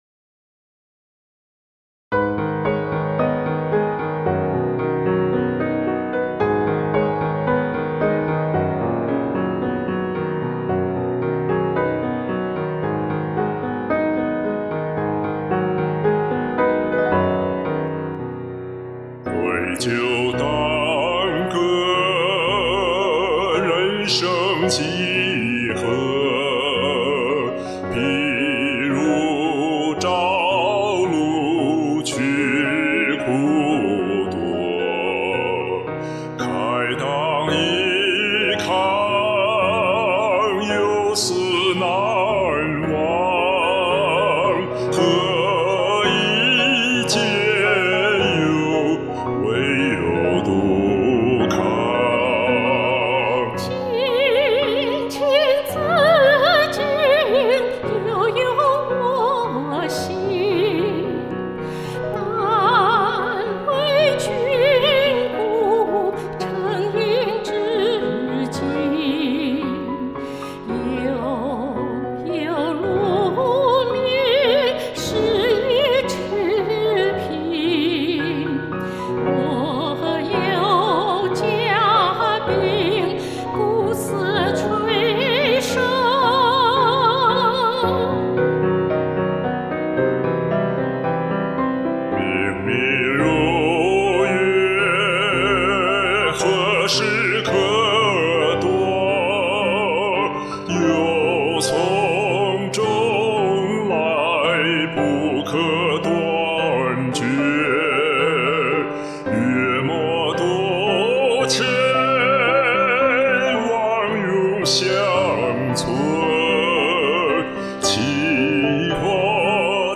根据歌词，采用了慢速的2/4。
因为能力有限，担心这样的曲风过于呆板乏味，所以想尝试男中音/女高音的结，采用对唱及重唱的形式。全曲分三段，第一段诗句的前半部，第二段诗句的后半部，第三段重复第一段诗句。在这三度中升了两次调，每次一个小二度。
B调伴奏